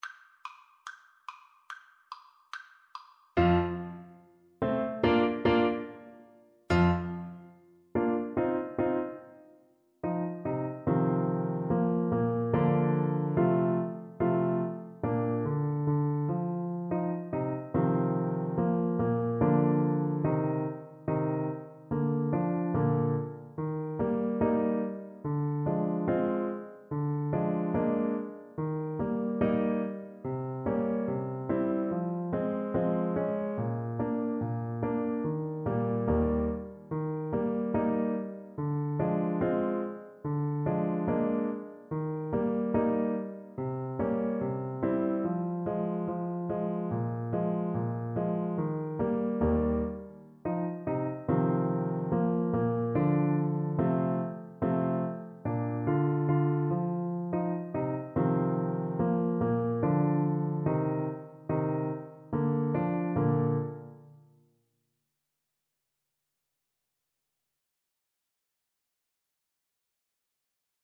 2/4 (View more 2/4 Music)
A3-F5
Trombone  (View more Intermediate Trombone Music)
Classical (View more Classical Trombone Music)
Joyful Music for Trombone
Humorous Music for Trombone